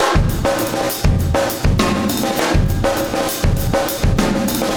Extra Terrestrial Beat 18.wav